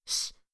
shh1.wav